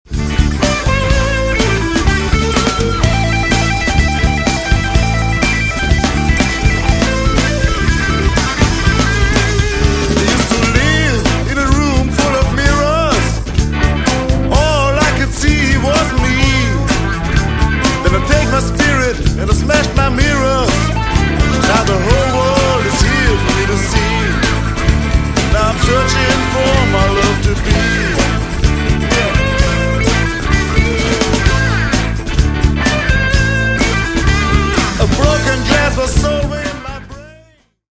Recorded at Electric Sounddesign Studio, Linz/Austria 2003.
guitars, lead vocals
bass, vocals
drums